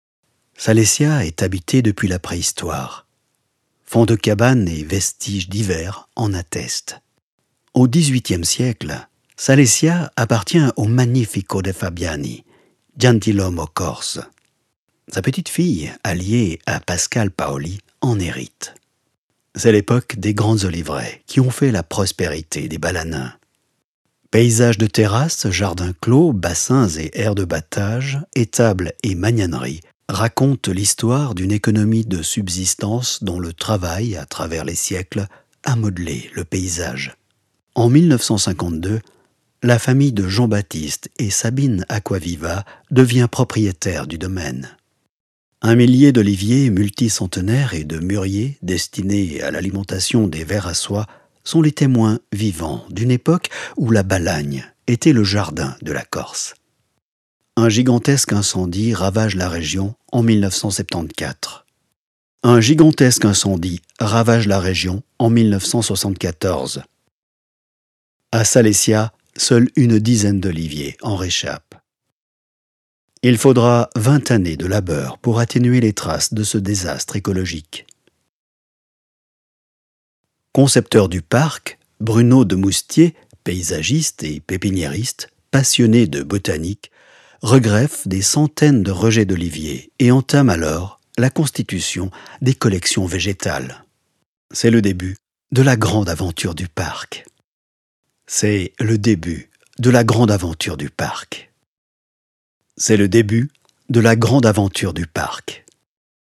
OFF-Kommentar Französisch (CH)